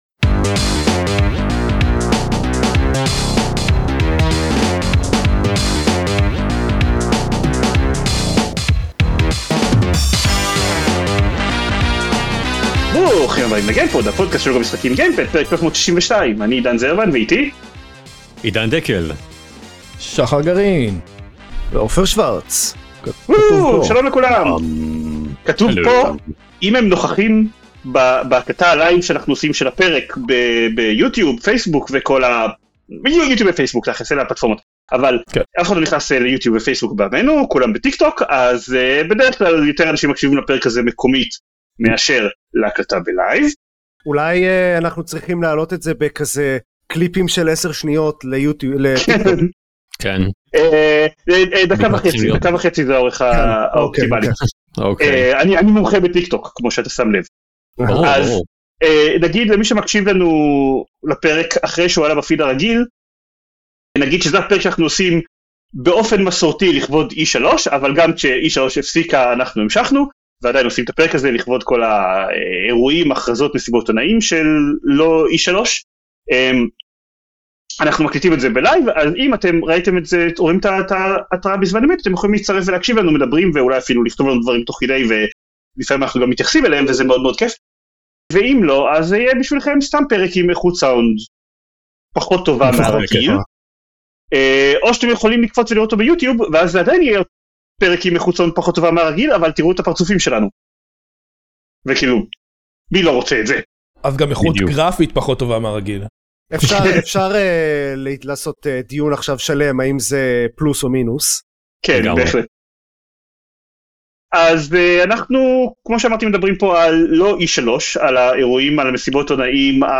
לכבוד שבוע מה-שהיה-פעם-E3 התאספנו סביב המיקרופונים שלנו, הפעלנו מצלמות ושיתפנו אתכם במחשבות שלנו לגבי הצהרות הגיימינג מהימים האחרונים.